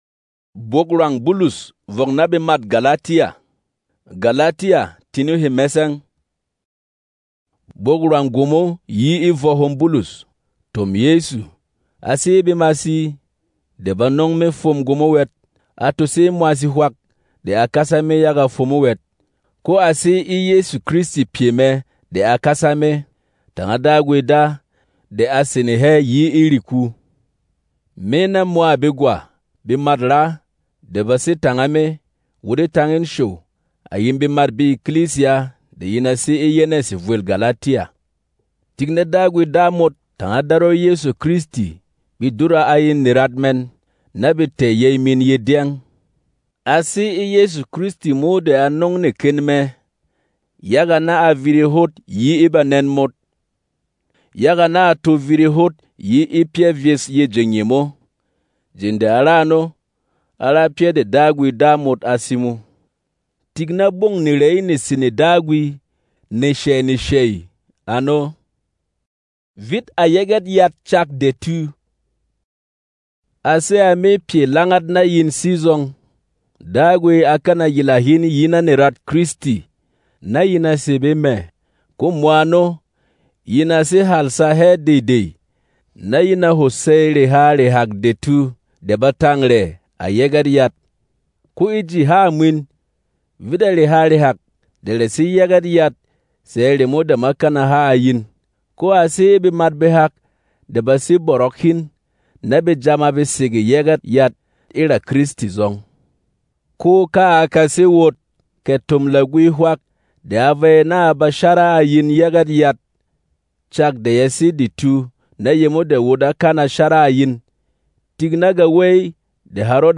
Berom MP3 Bible ⚙